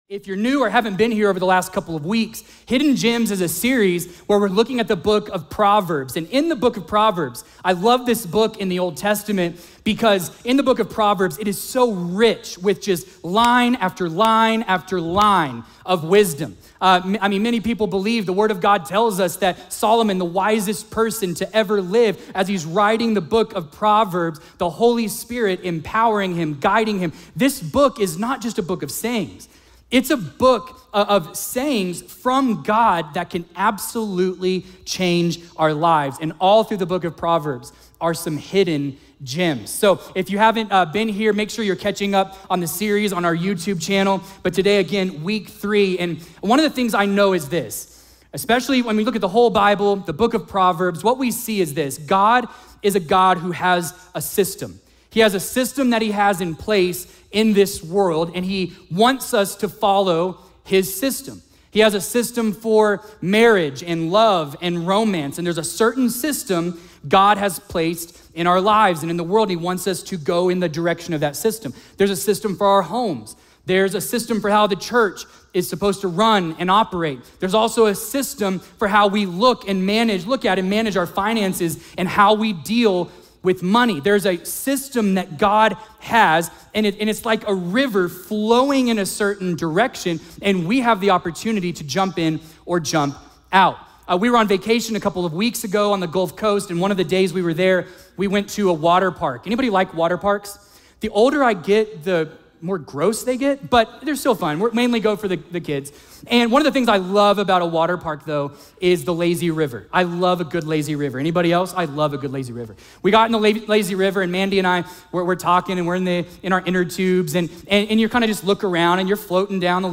A message from the series "Homework." Join us in Week 4 of our 'Home Work' series as we explore the beauty and purpose of singleness! Dive into key scriptures, discover the benefits of being single, and reflect on how you can grow in this season.